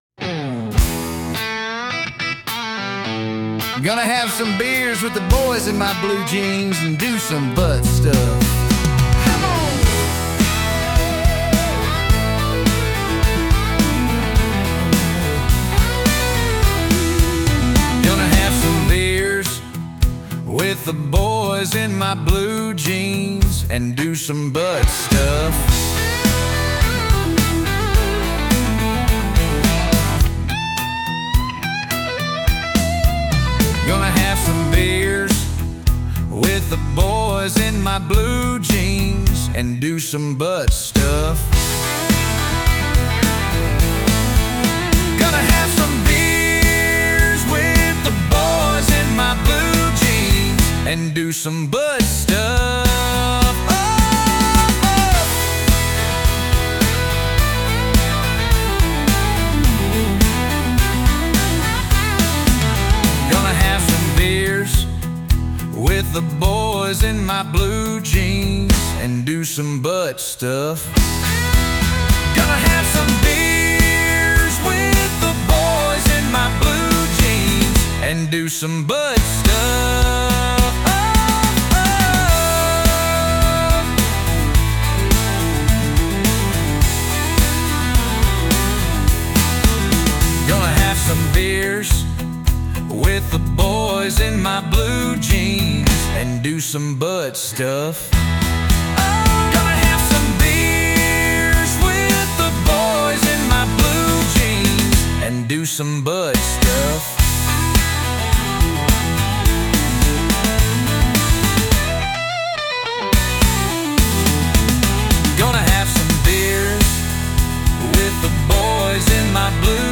country, male vocals